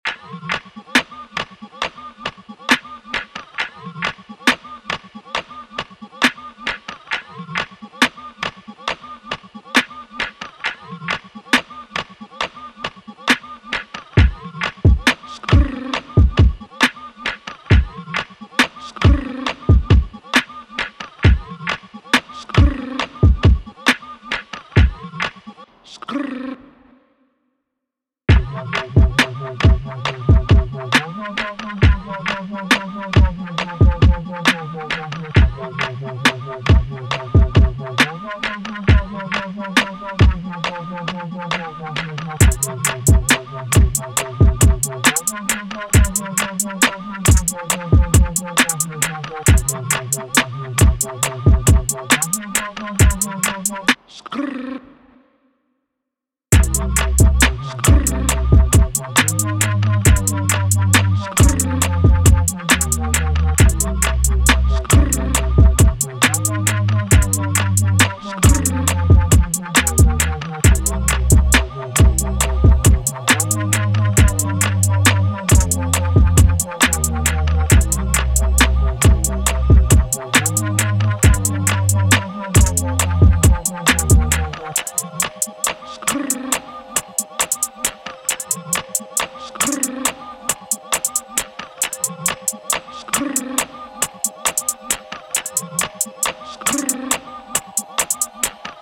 Hip Hop
C Minor